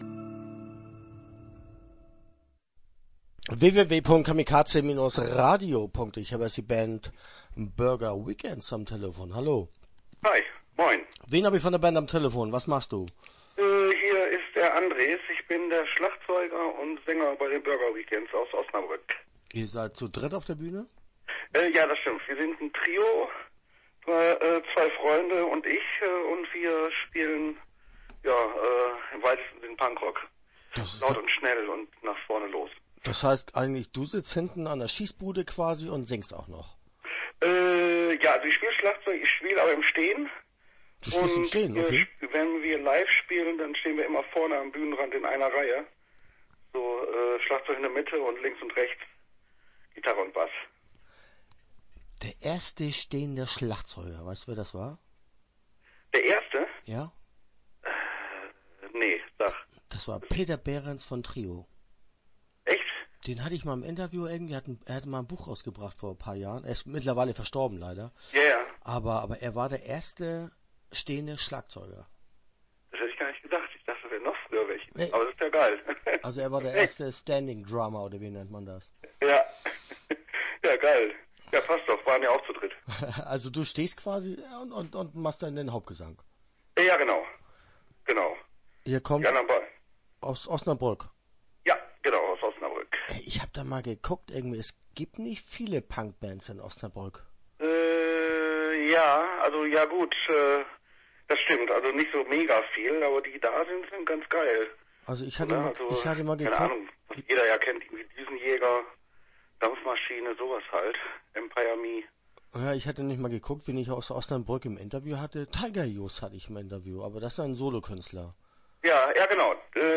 Burger Weekends - Interview Teil 1 (10:25)